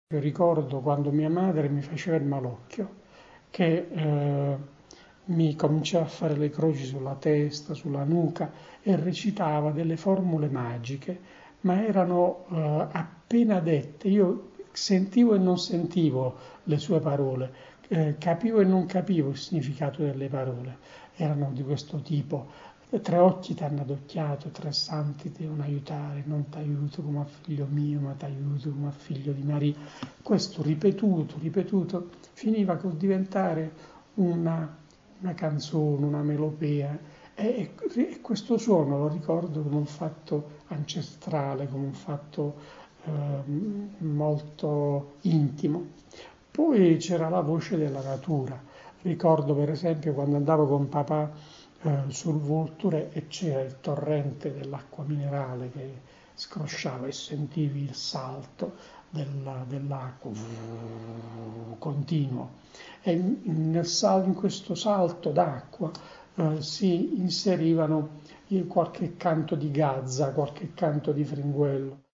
Intervista a Raffaele Nigro, scrittore e giornalista